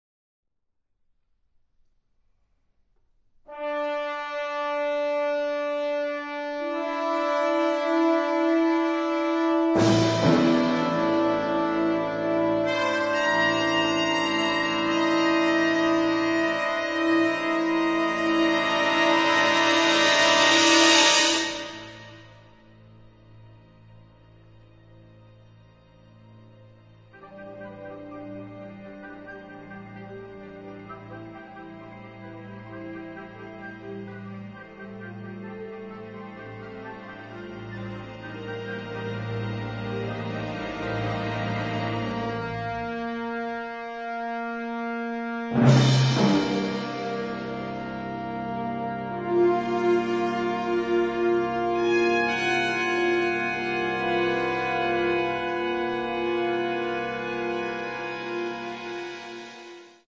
Bassoon
Clarinet
Flute
French Horn
Oboe
Percussion
Strings
Timpani
Trombone
Trumpet
Tuba
BBC Maida Vale